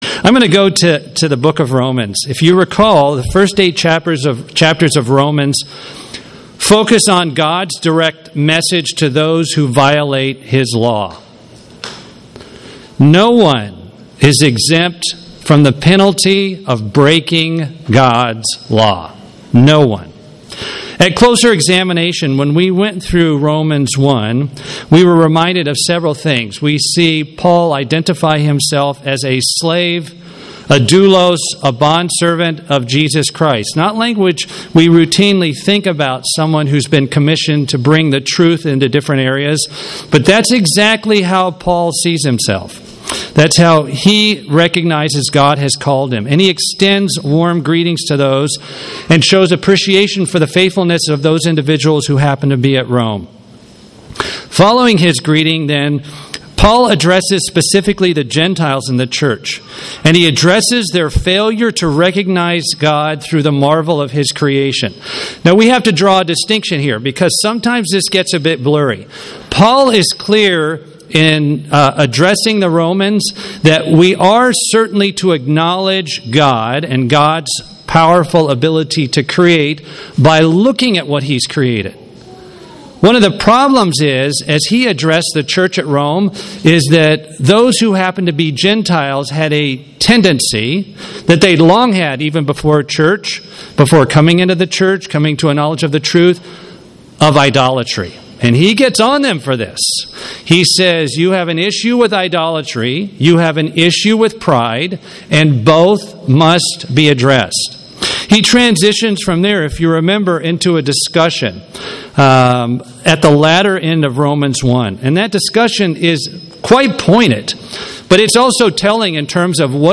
Rome Paul righteous righteousness Peter Simon Magus Simon the magician Corinth sermon Transcript This transcript was generated by AI and may contain errors.